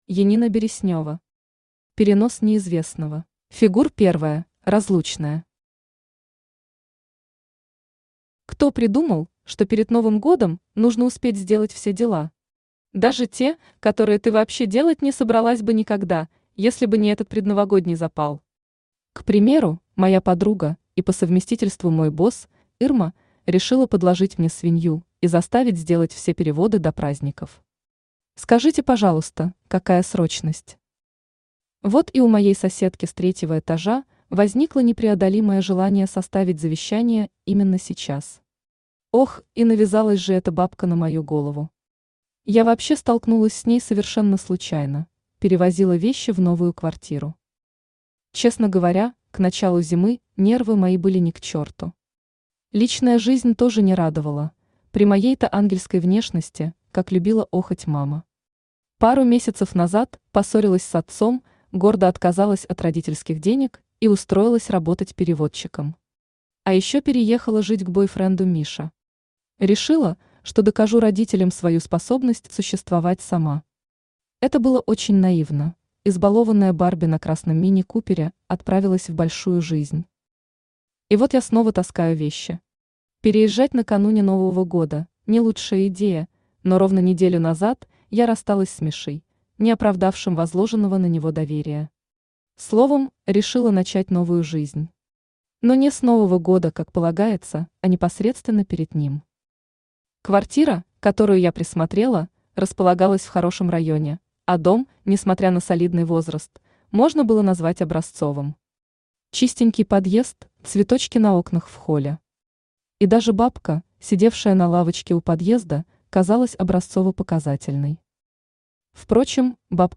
Aудиокнига Перенос неизвестного Автор Янина Олеговна Береснева Читает аудиокнигу Авточтец ЛитРес.